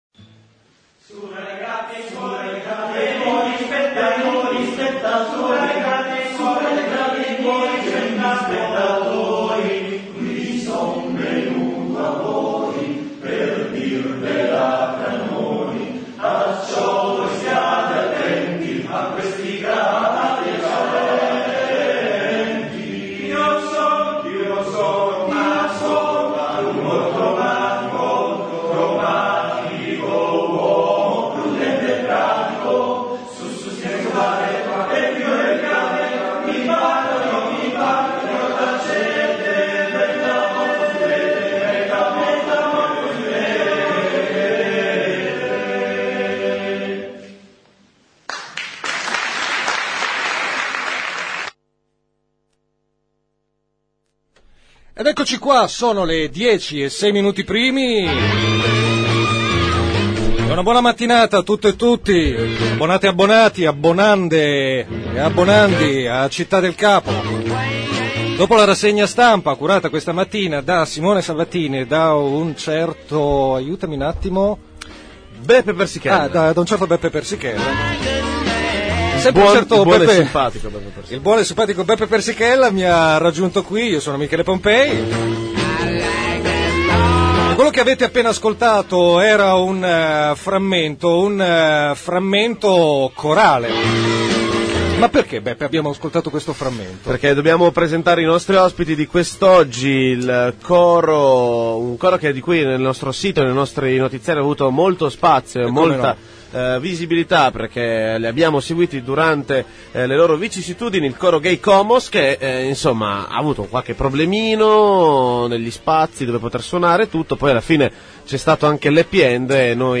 Ecco l’intervista ai ragazzi del coro audio_komos